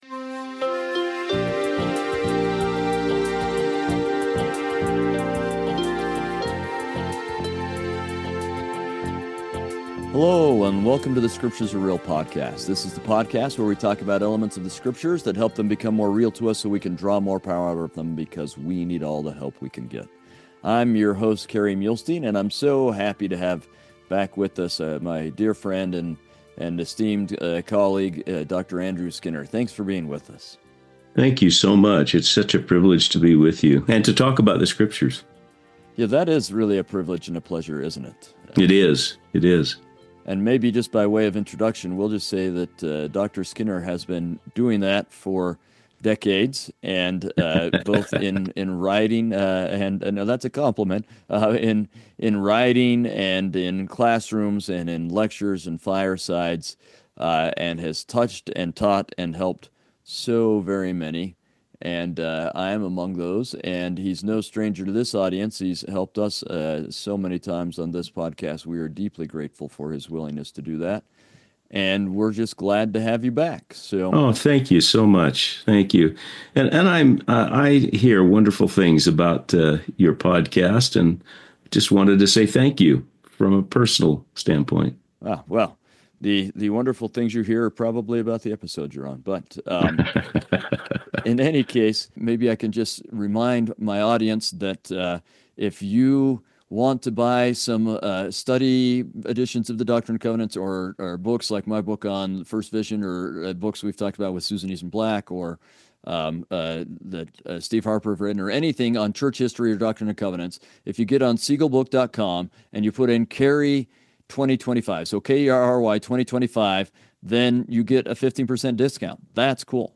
We interview both experts (people with language, archaeological, historical backgrounds, etc.), and lay folks, and explore times when the scriptures became real to them. This is done from the viewpoint of members of the Church of Jesus Christ of Latter-day Saints.